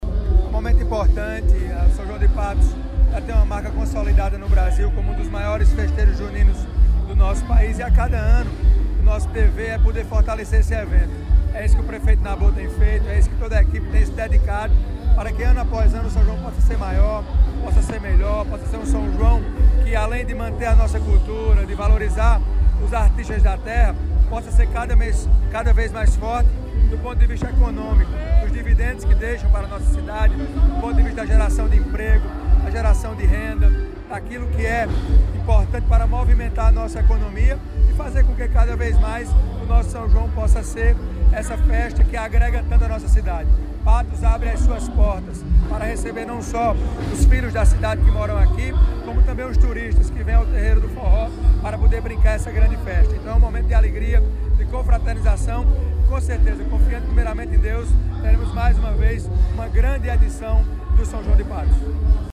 Ouça a avaliação do deputado federal Hugo Motta: